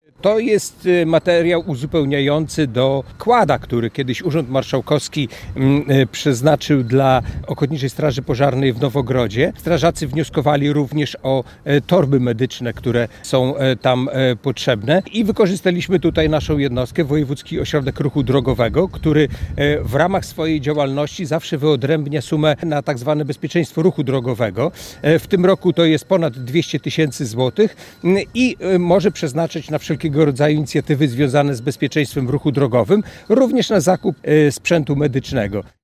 Podczas przekazania sprzętu członek zarządu woj. podlaskiego, Jacek Piorunek mówił, że to dopełnienie wsparcia jakie wcześniej otrzymała OSP w Nowogrodzie: